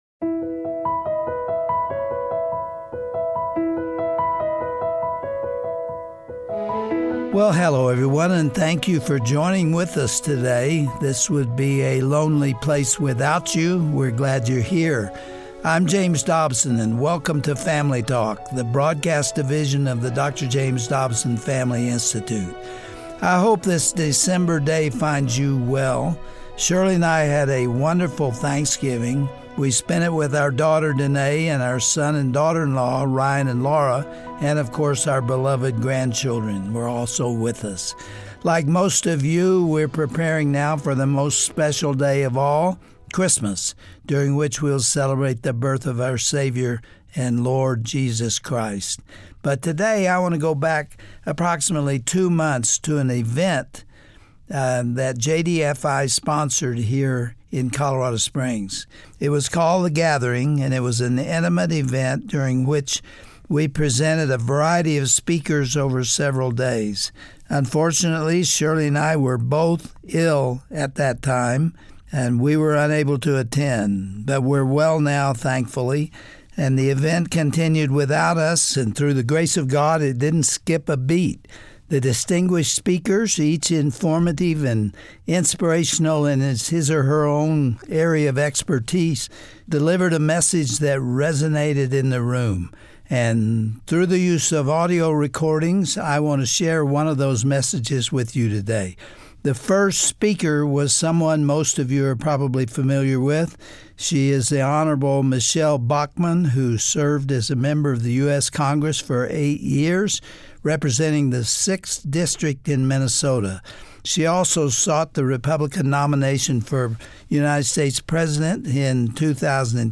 On today's edition of Family Talk, Congresswoman Michele Bachmann explains that America, like Israel in Isaiah chapter 22, is being punished for rejecting God. We need to follow King Josiah's example found in Second Kings 22, when he rediscovered God's Word and urged the nation to repent.
Host Dr. James Dobson